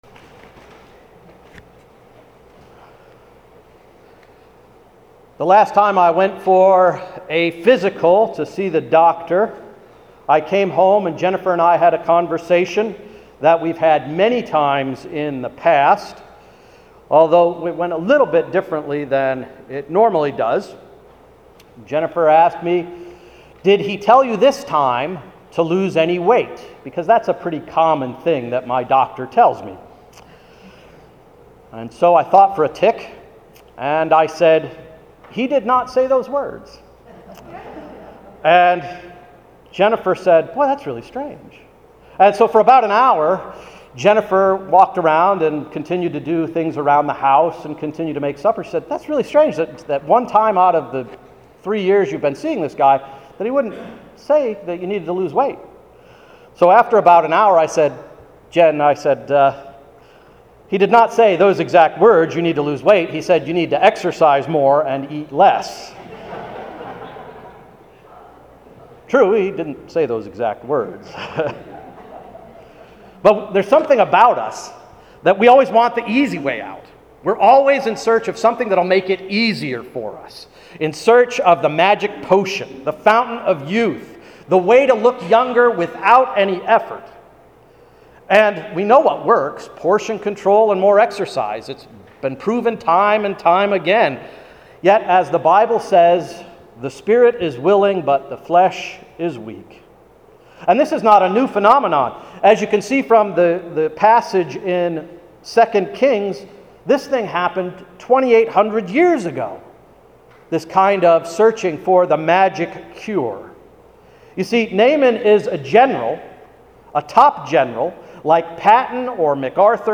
Sermon of July 7, 2013–“You Want Me To Do What?”